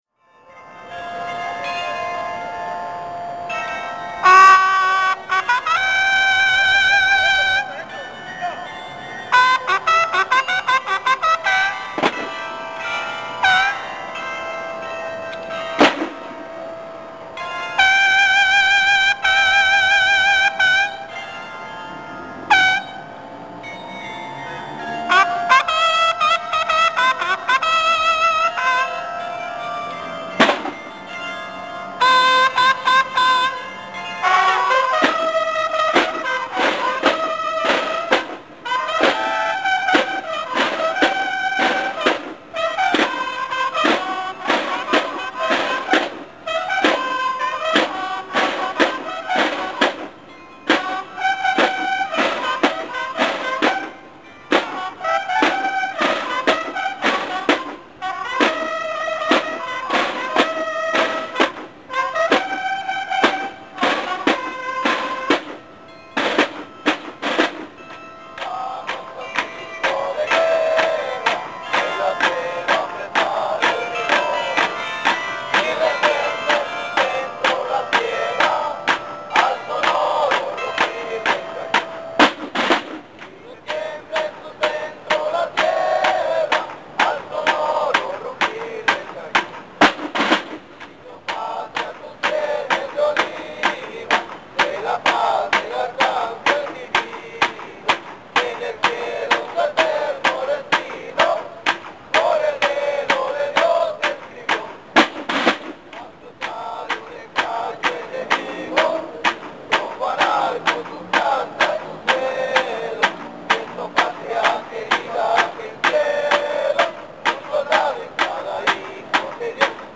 Izado de bandera parque central Tuxtla Gutiérrez
En la mañana se realiza el izado de la bandera nacional en el parque central al mismo tiempo se escuchan las campanas de la iglesia de San Marcos.
La primera marcha de la orquesta militar es TOQUE DE BANDERA: